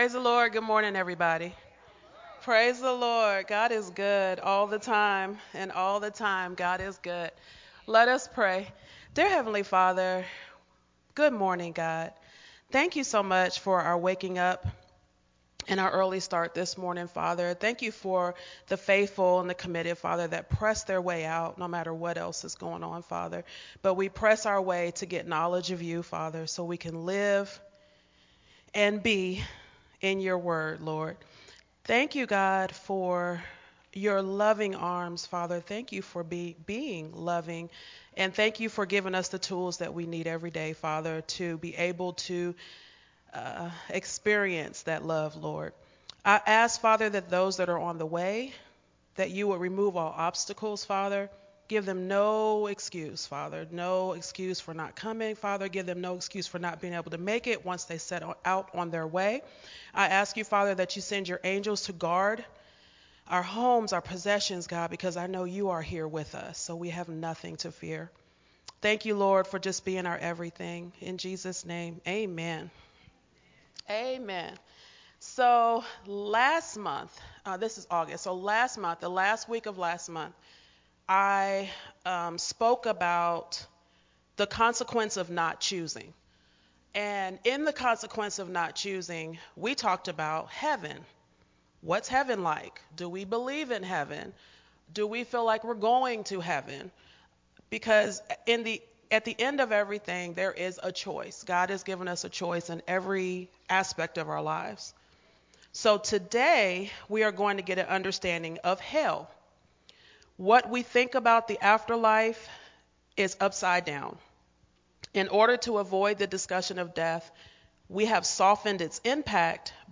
recorded at Unity Worship Center